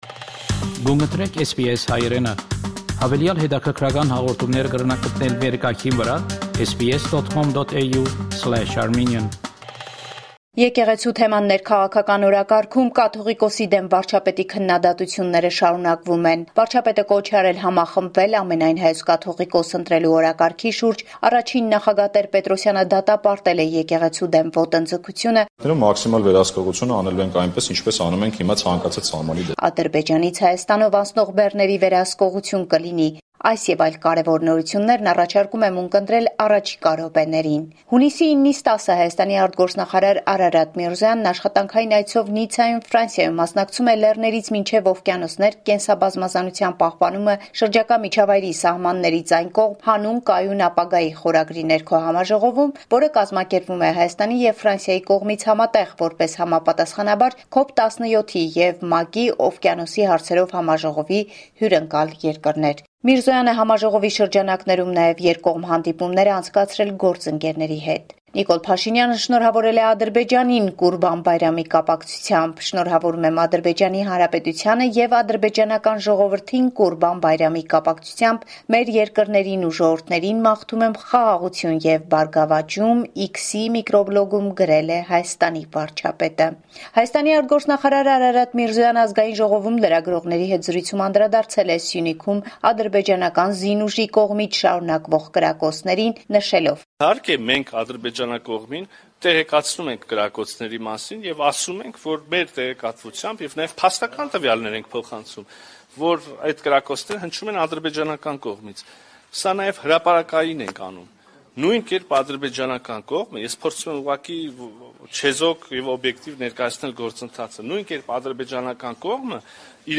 Latest news from Armenia, Artsakh and the Diaspora from our reporter